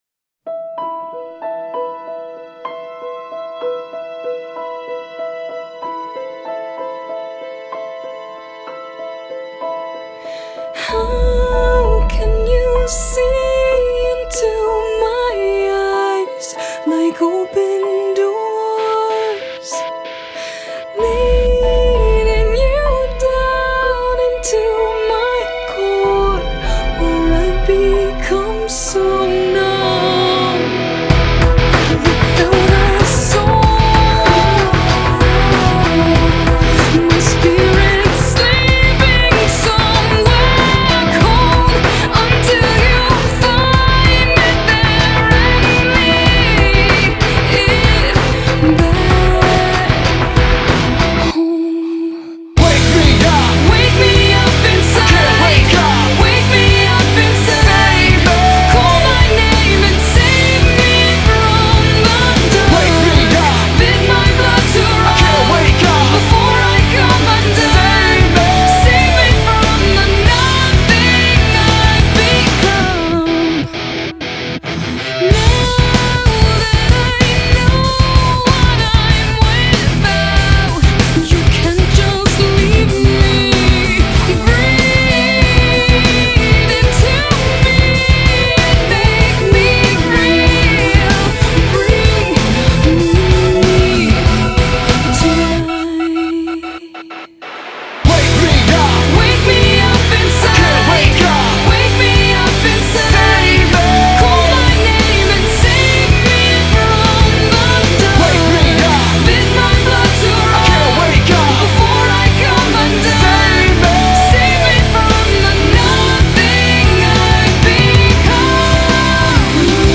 BPM48-95
Audio QualityPerfect (High Quality)
Re-uploaded with better audio and better sync.